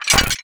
CapersProject/MECHANICS_Spark_01_mono.wav at 4a06cc0565e5c3669dc862d907fce4b4b9665be2
MECHANICS_Spark_01_mono.wav